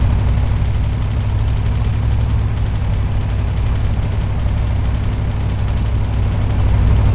car-engine-2.ogg